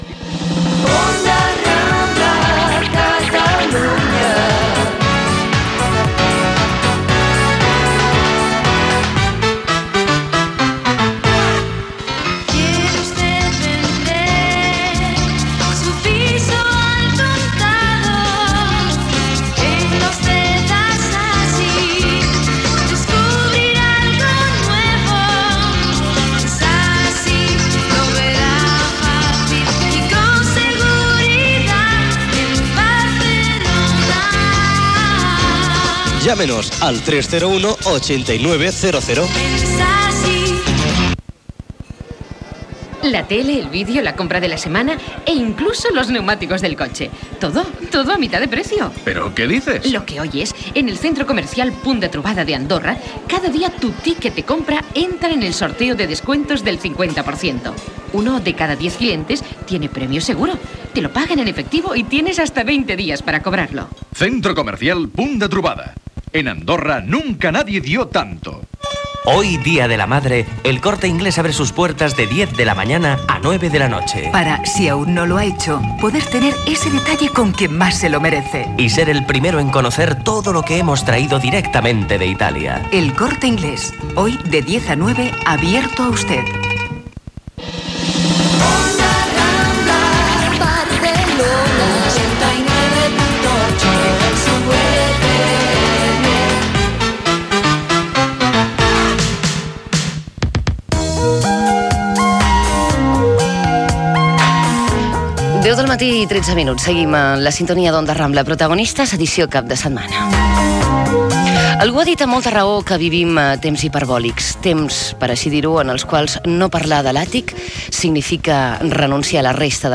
Indicatiu de l'emissora, publicitat,, indicatiu, entrevista
Entreteniment
FM